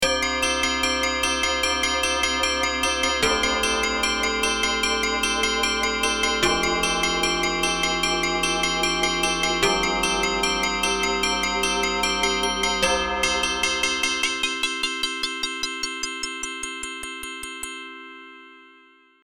Bell-Sounds ermöglicht die Klangsynthese von ESQ-1/SQ80/SQ80V ebenfalls: